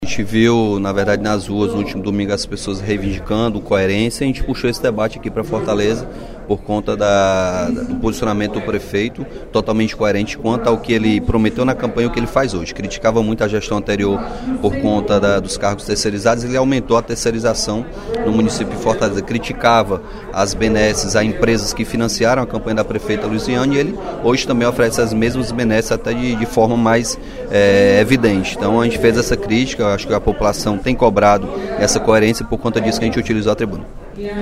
O deputado Capitão Wagner (PR) criticou a gestão do prefeito Roberto Cláudio, durante pronunciamento no primeiro expediente da sessão plenária da Assembleia Legislativa desta terça-feira (15/03). Segundo o parlamentar, a cidade de Fortaleza nunca esteve “tão suja, desorganizada e doente”.